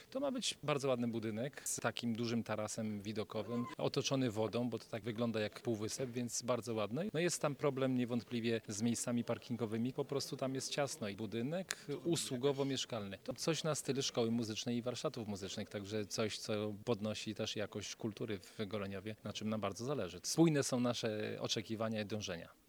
Są małe problemy dotyczące warunków zabudowy, ale na pewno uda się wypracować kompromis, który zadowoli wszystkich – mówi wiceburmistrz Tomasz Banach.